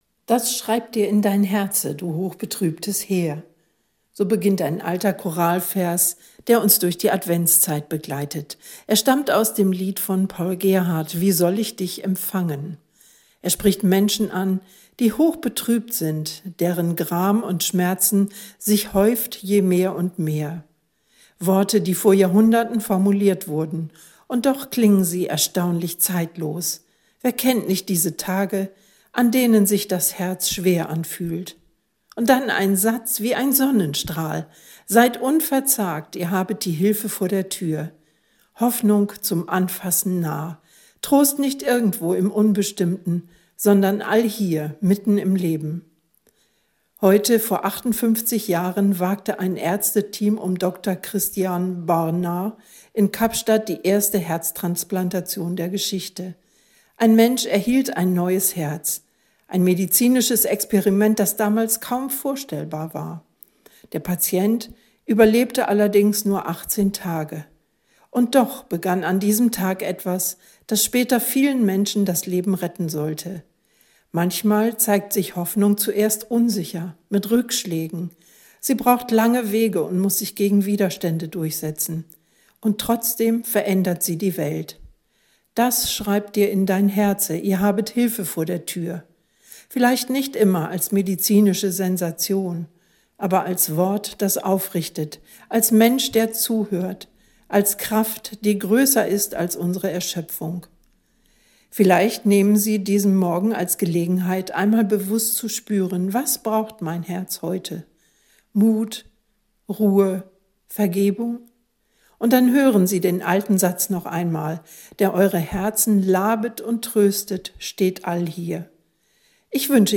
Radioandacht vom 3. Dezember